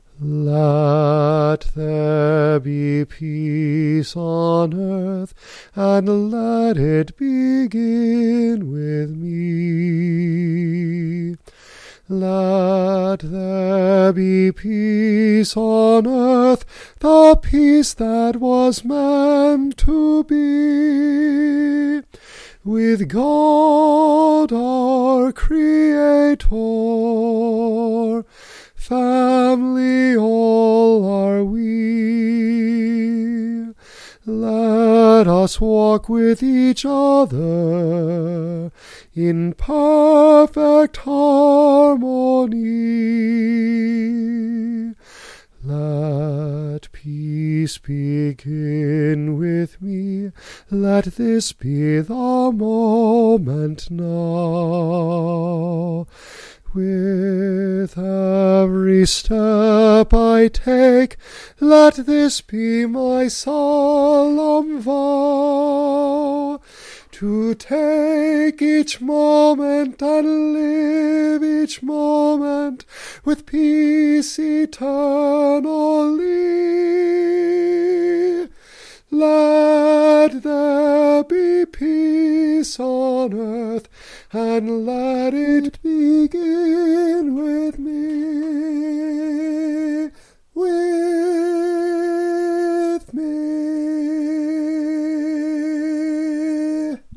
Here is the audio version, sung by moi!